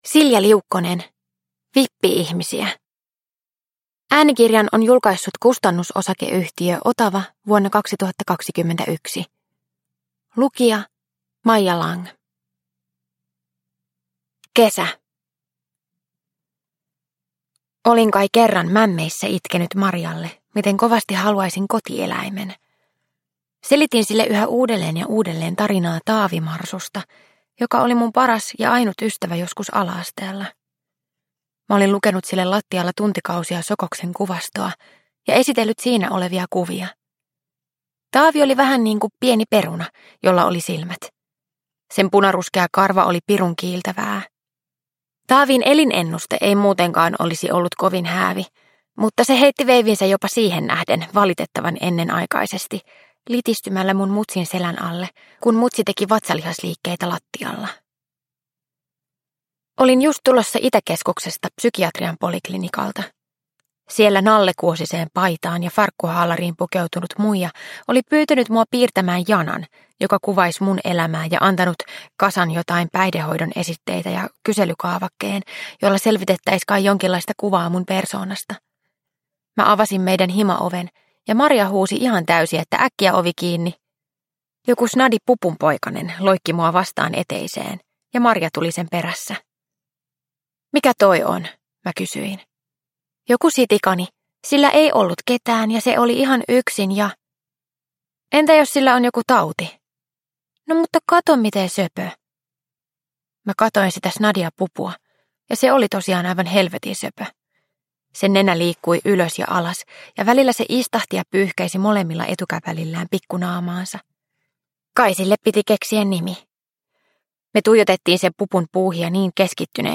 Vippi-ihmisiä – Ljudbok